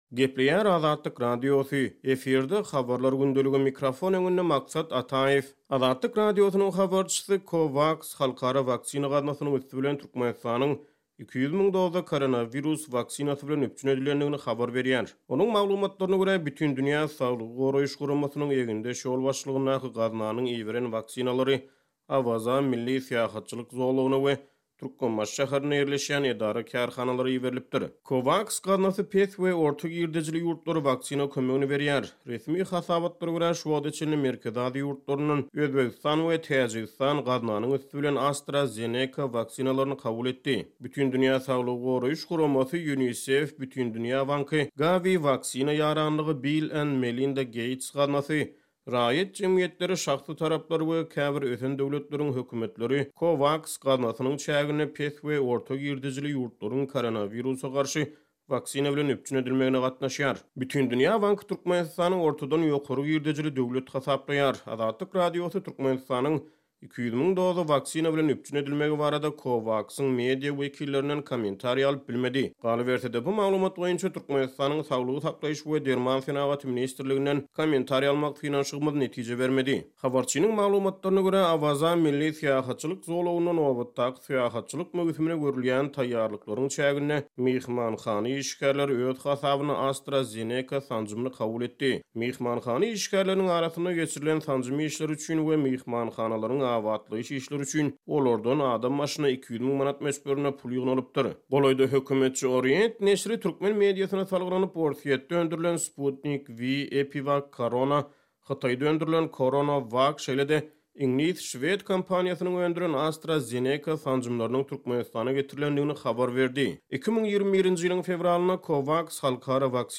Azatlyk Radiosynyň habarçysy COVAX halkara waksina gaznasynyň üsti bilen Türkmenistanyň 200 müň doza koronawirus waksinasy bilen üpjün edilendigini habar berýär.